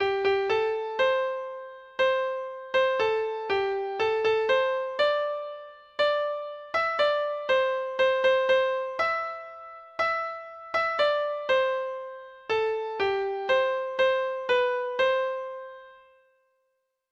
Treble Clef Instrument version
Folk Songs
Traditional Music of unknown author.